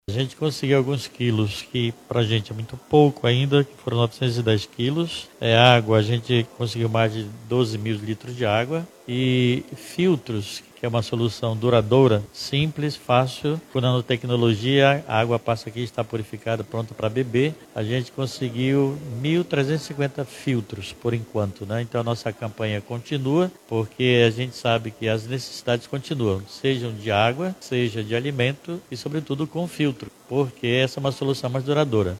Nessa terça-feira, 26 de novembro, durante coletiva de imprensa na Cúria Metropolitana de Manaus, foi apresentada a campanha humanitária “Água é Vida, Doe Vida”.